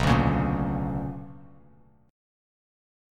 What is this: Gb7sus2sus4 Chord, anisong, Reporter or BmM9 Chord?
BmM9 Chord